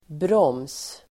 Uttal: [bråm:s]